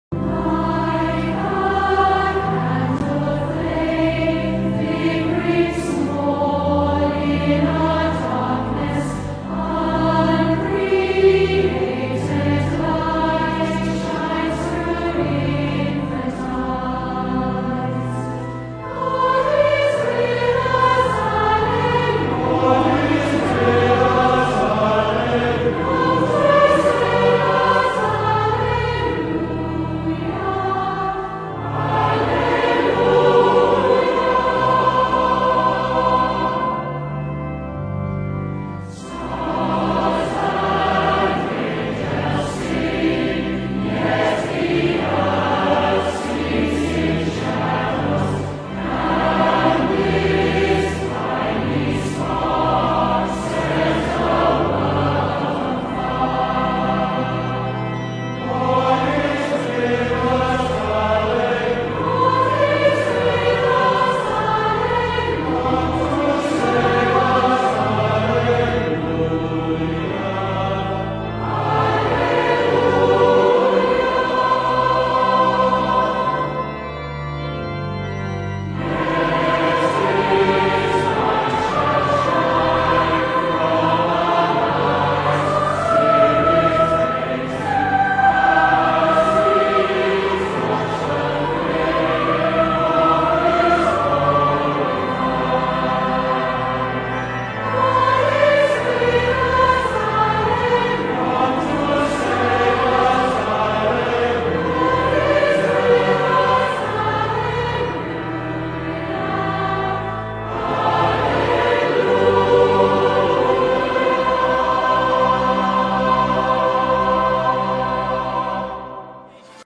is a gentle and contemplative hymn